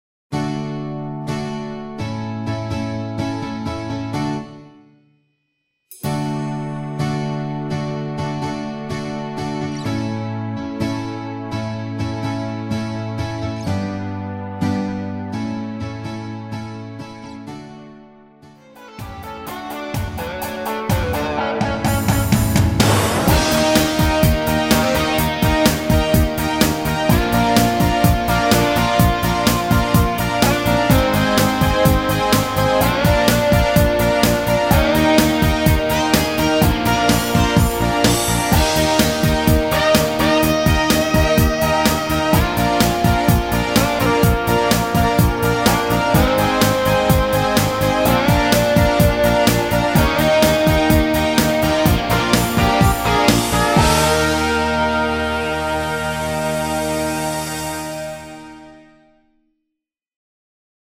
(시작 부분 기타 브레이크 다음 노래 들어가시면 됩니다.)
앞부분30초, 뒷부분30초씩 편집해서 올려 드리고 있습니다.